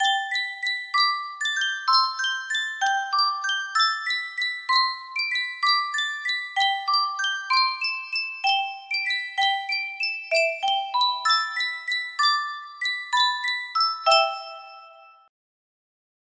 Yunsheng Music Box - Unknown Tune 1591 music box melody
Full range 60